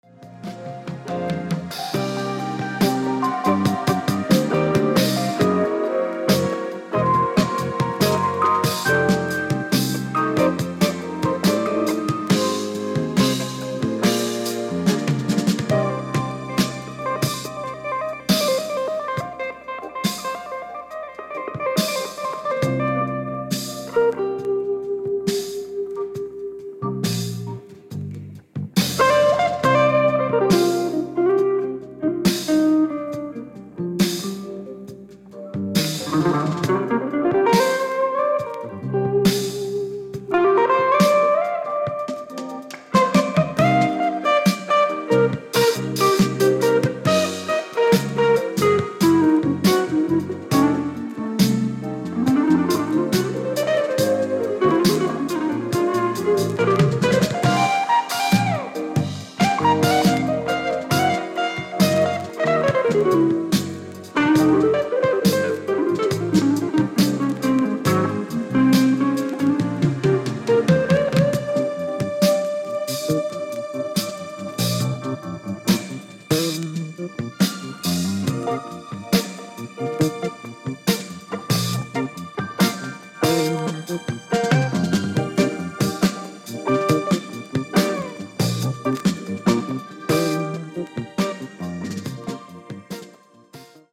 軽快なサウンドが世界的に注目されてますね！！！